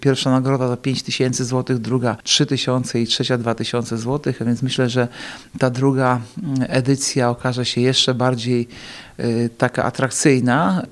– Na prace czekamy do 30 lipca – mówi zastępca prezydenta Ełku Artur Urbański.